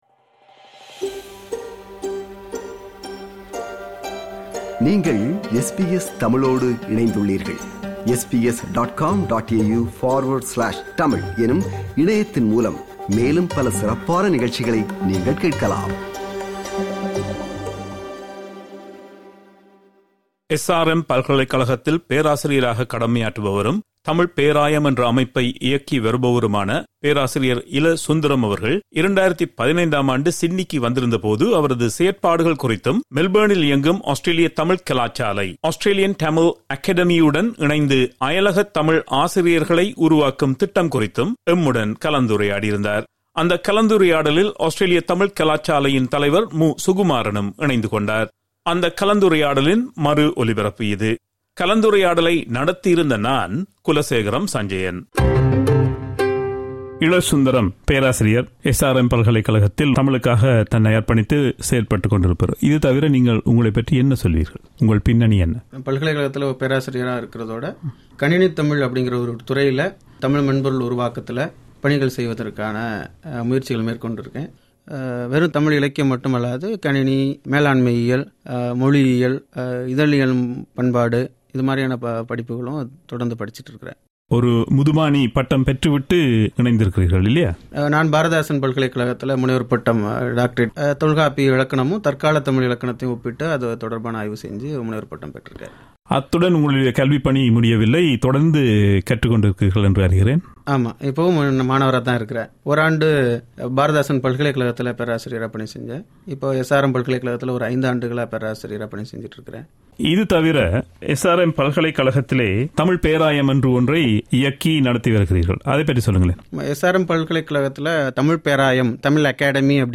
அந்த கலந்துரையாடலின் மறு ஒலிபரப்பு இது.